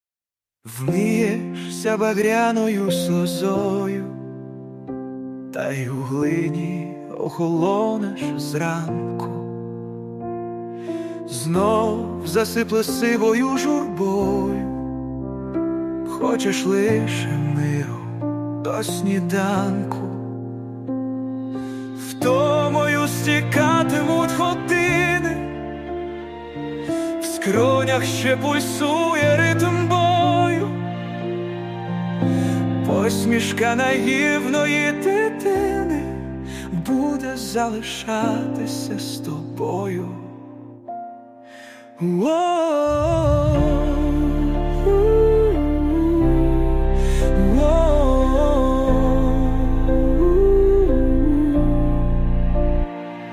Слова - автора, музика - ШІ.
ТИП: Пісня
СТИЛЬОВІ ЖАНРИ: Ліричний